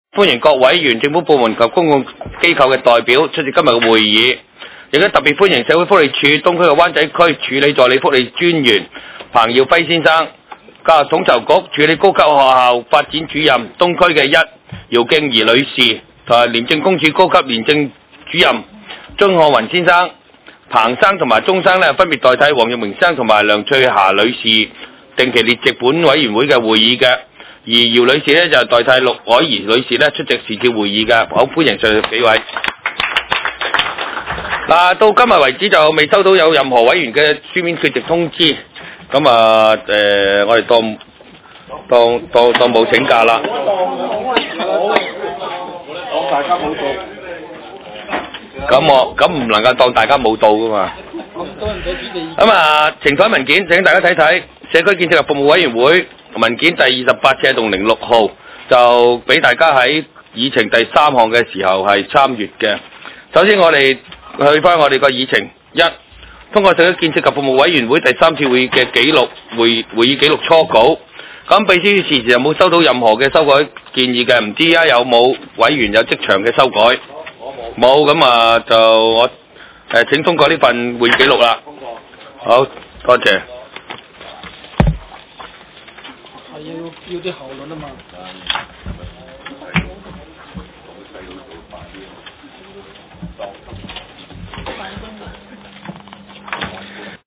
社區建設及服務委員會第四次會議
東區法院大樓11樓東區議會會議室